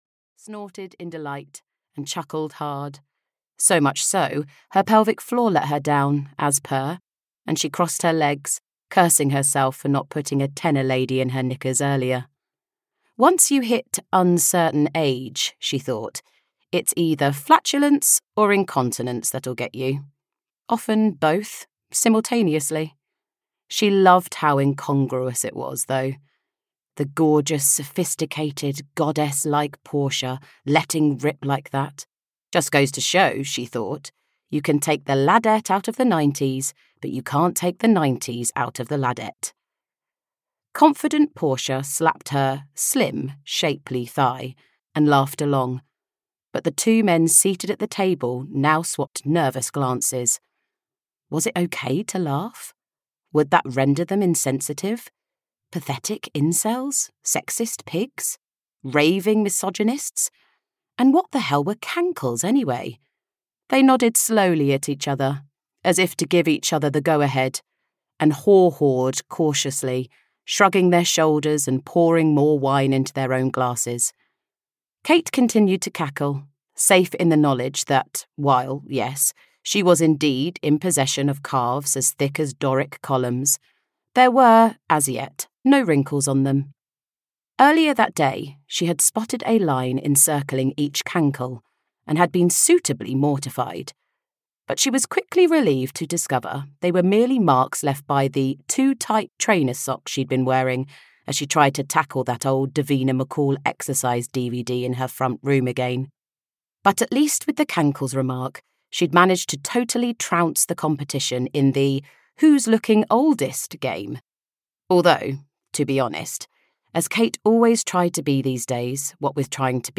The Glory Years (EN) audiokniha
Ukázka z knihy